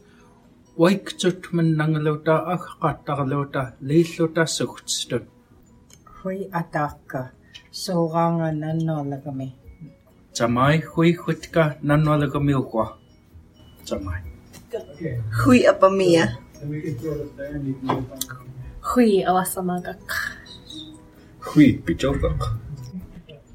Velar fricatives (including maybe syllabic [xʷ]?), retroflex stops, uvular stops, simple vowels…